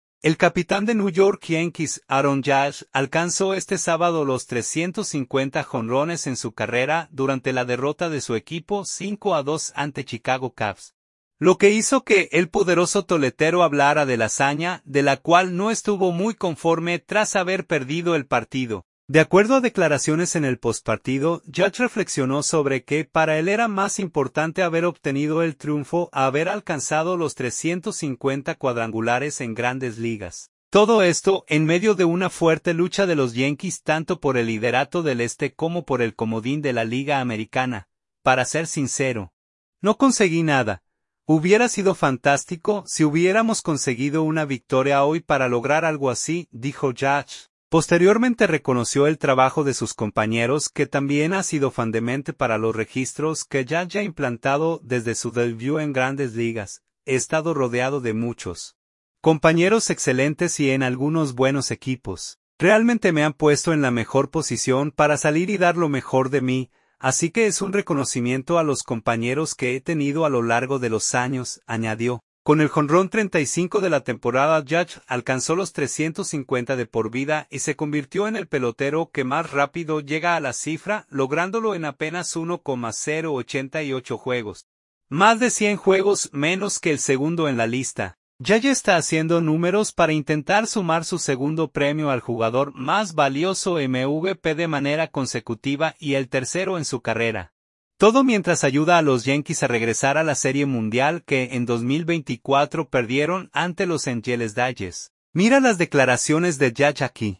De acuerdo a declaraciones en el postpartido, Judge reflexionó sobre que para él era más importante haber obtenido el triunfo a haber alcanzado los 350 cuadrangulares en Grandes Ligas, todo esto en medio de una fuerte lucha de los Yankees tanto por el liderato del Este como por el comodín de la Liga Americana.